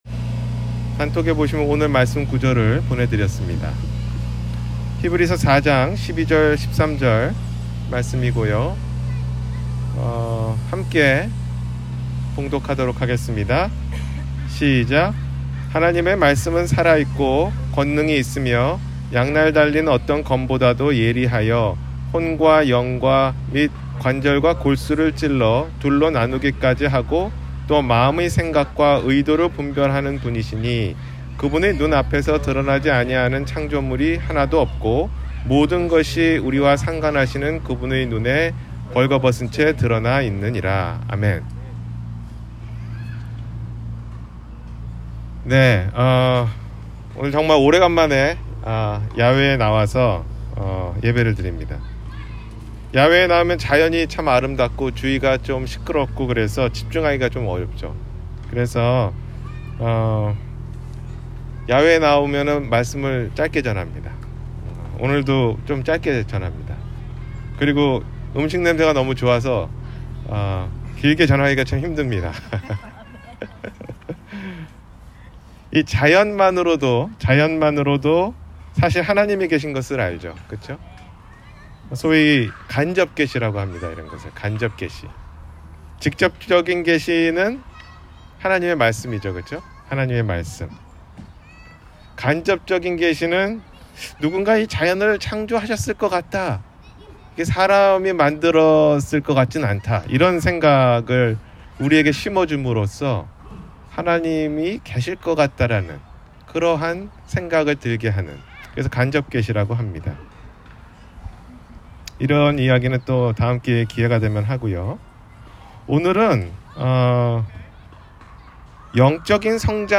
영적성장을 못하는 이유-주일설교